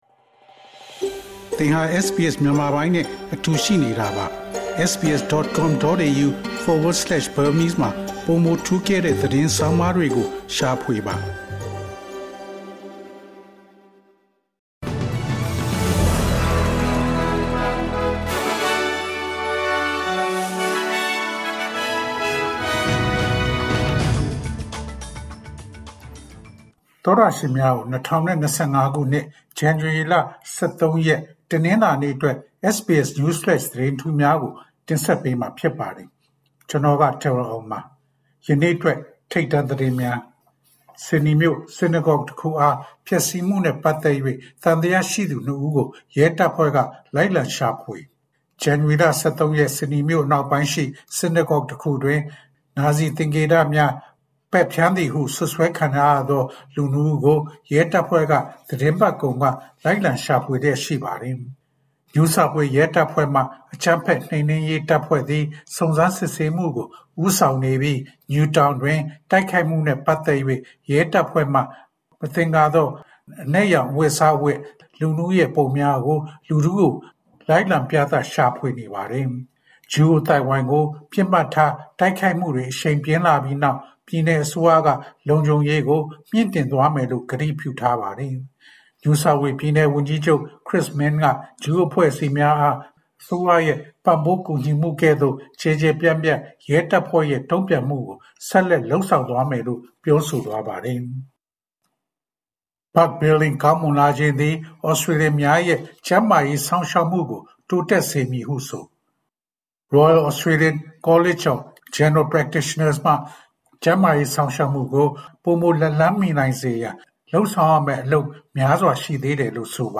ALC: January ၁၃ ရက် ၂၀၂၅ ခုနှစ်, SBS Burmese News Flash.